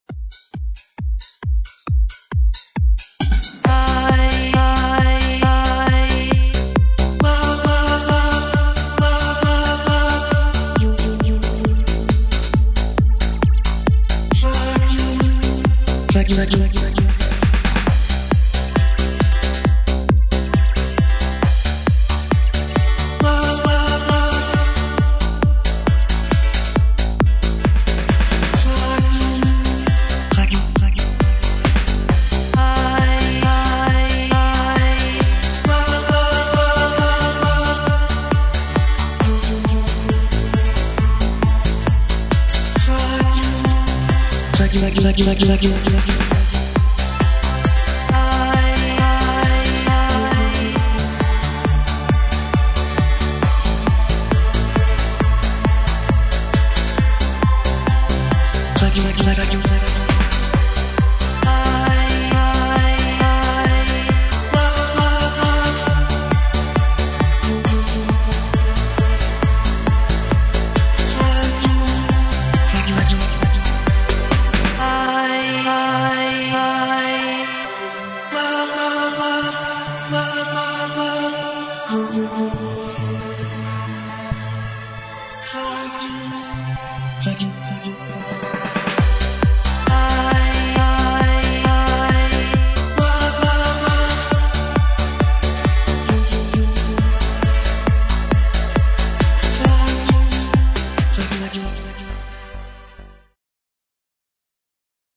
Музыкальный хостинг: /Электронная